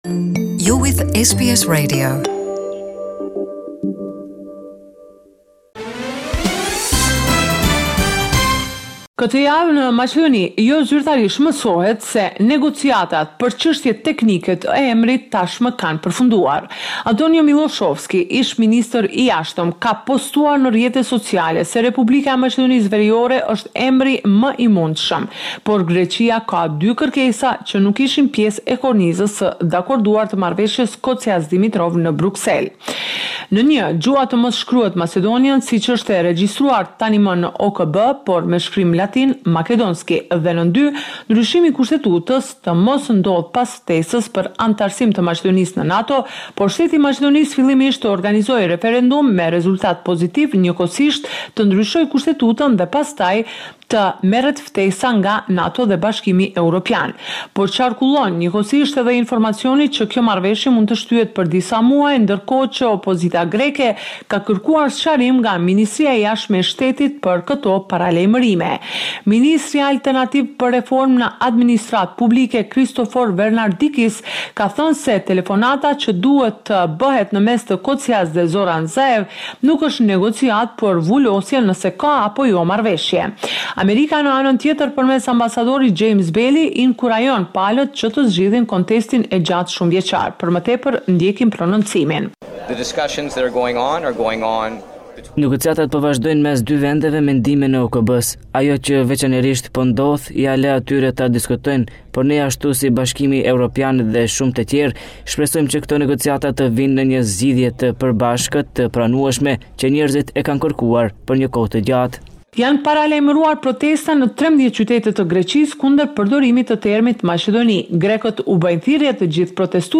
The weekly report with the latest developments in Macedonia